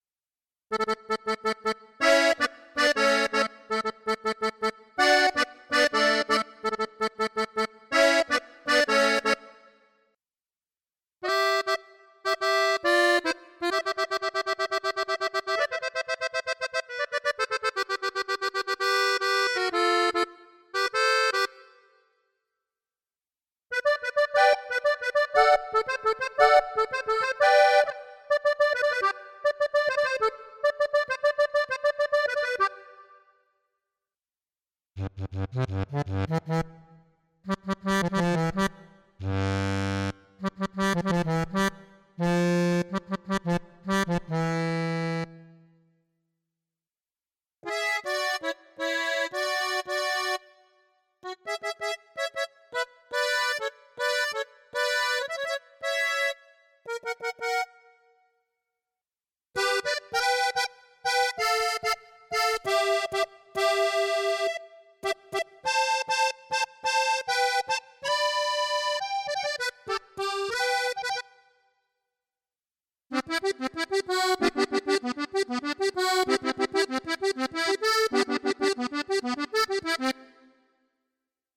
KORG M3 Acordeones
Esta librería ha sido masterizada para ofrecer una respuesta táctil y sonora idéntica a los instrumentos originales, capturando el “aire” y la vibración característica que define al género regional mexicano, norteño y de balada.
• Gabbanelli: El estándar de oro para el sonido norteño y tejano. Incluye registros brillantes y con ese “punch” necesario para los adornos y solos más exigentes.
• Hohner Corona: El sonido clásico y tradicional de la música de acordeón. Capturamos la calidez y el tono auténtico de los modelos Corona II, ideales para polkas y huapangos.
• Weltmeister: Reconocido por su precisión alemana y su tono robusto, perfecto para darle cuerpo y una textura diferente a tus producciones y presentaciones en vivo.
• Muestreo Multi-capa: Los samples responden a la velocidad de tu ejecución, permitiendo matices desde notas suaves hasta ataques fuertes.
• Optimizado para Korg M3: Programado específicamente para aprovechar el motor de síntesis del M3, con efectos de reverb y chorus ajustados para un sonido profesional inmediato.
Accordions.mp3